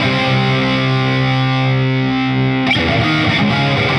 Index of /musicradar/80s-heat-samples/120bpm